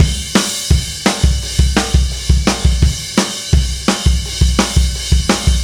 Indie Pop Beat 05 Crash.wav